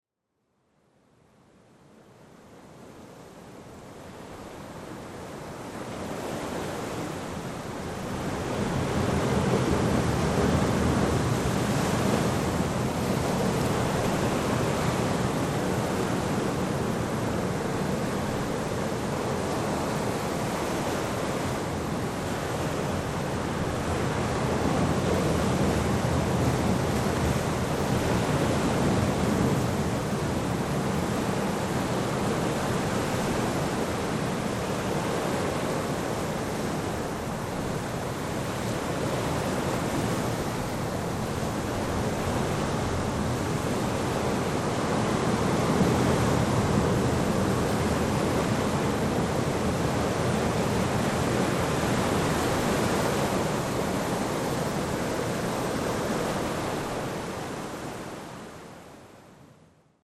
6 Stunden Meeresrauschen mit Möwen (MP3)
Bei Geräuschaufnahmen sind diese ebenfalls in 44.1 kHz Stereo aufgenommen, allerdings etwas leister auf -23 LUFS gemastert.
44.1 kHz / Stereo Sound
Lautstärke: -23 LUFS